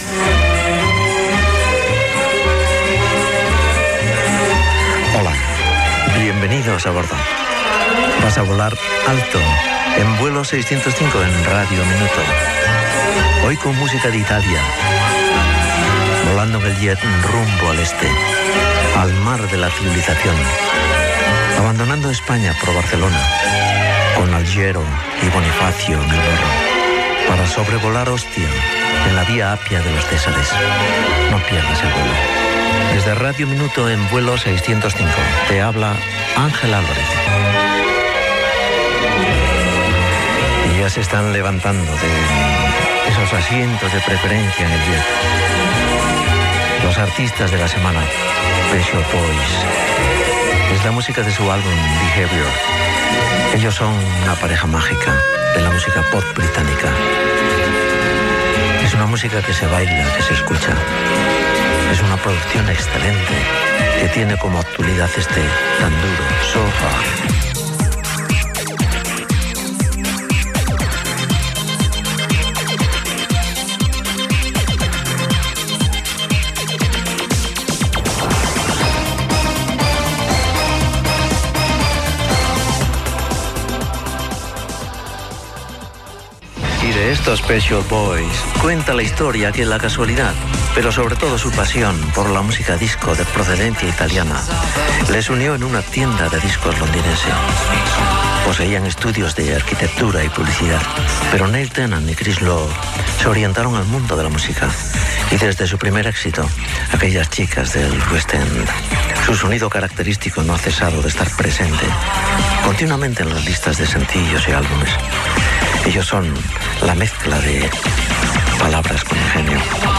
Identificació del programa, presentació de l'espai i d'un tema musical. Comentari sobre el grup Pet Shop Boys.
Musical
FM